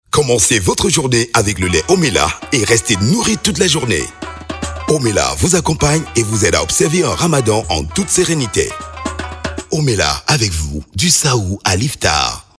spot-radio-promo-guinee-conakry_157_5.mp3